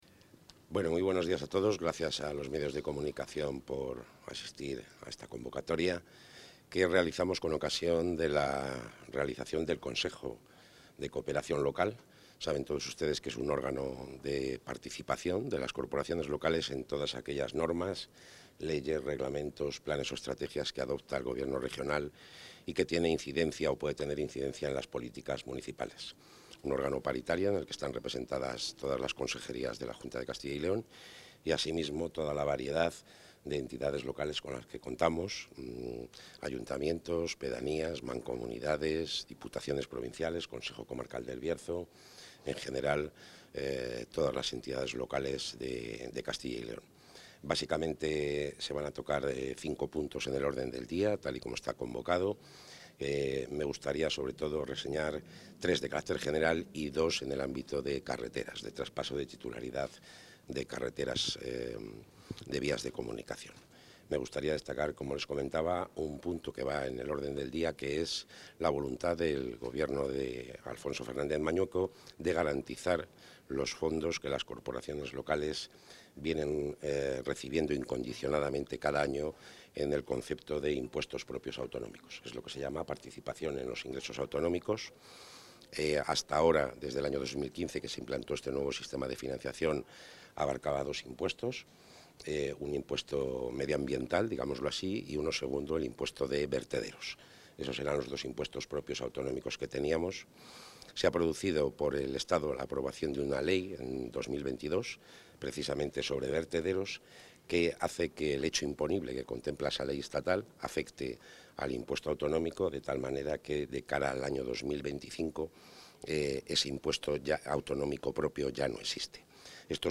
Declaraciones del consejero.